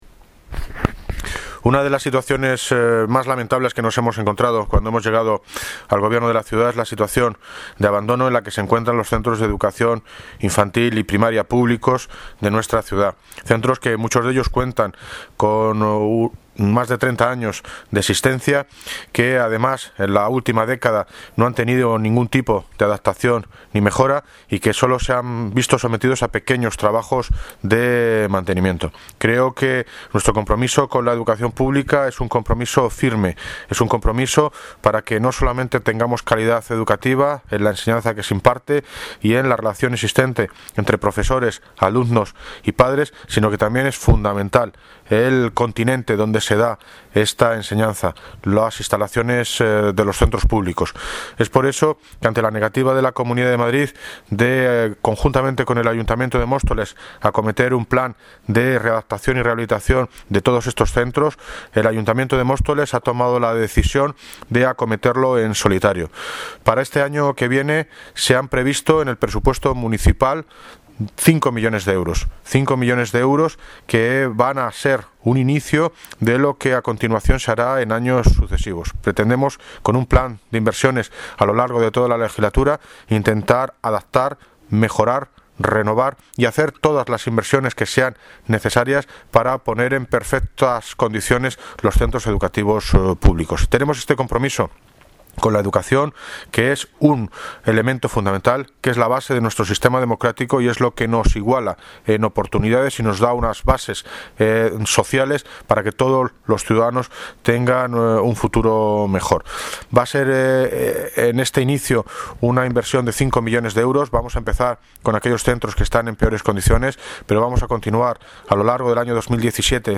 David Lucas (Alcalde) sobre el Plan de Mejora y Rehabilitación de colegios públicos